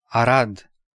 Arad (Romanian pronunciation: [aˈrad]
Ro-Arad.ogg.mp3